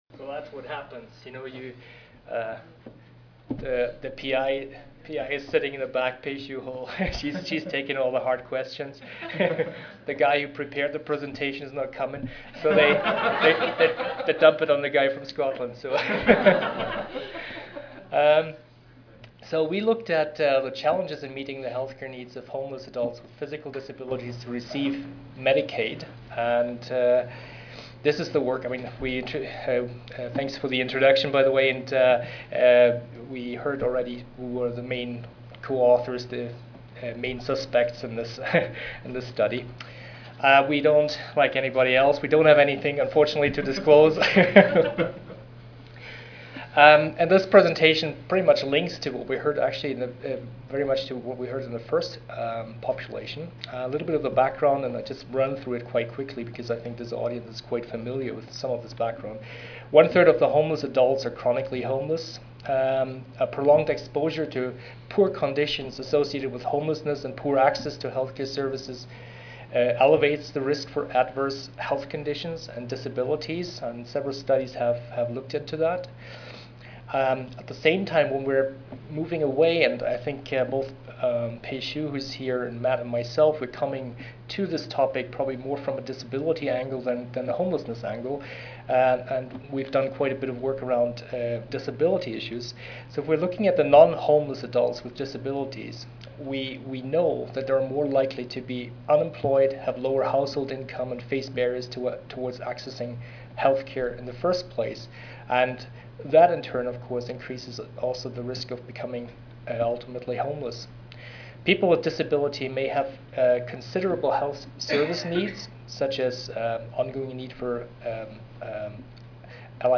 4167.0 Evidence Based Practices for Homelessness Services Tuesday, November 9, 2010: 12:30 PM - 2:00 PM Oral Session Objectives: 1.